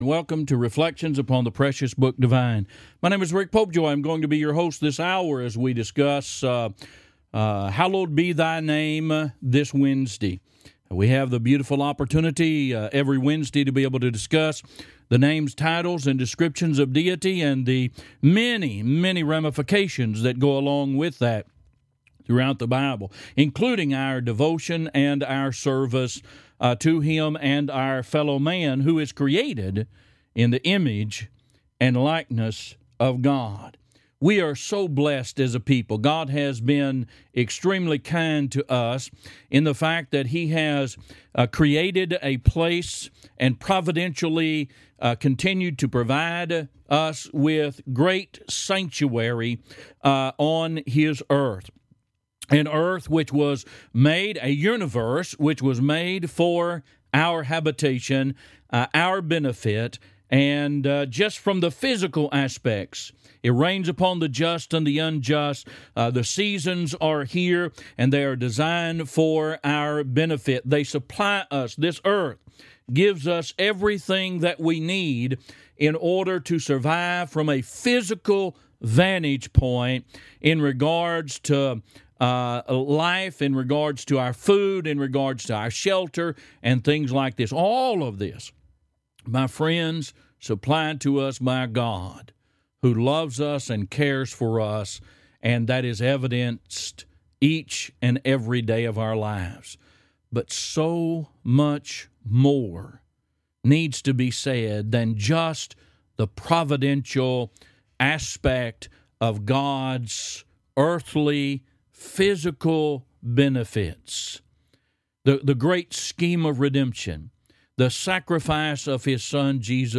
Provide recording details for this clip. Program Info: Live program from the Nesbit church of Christ in Nesbit, MS.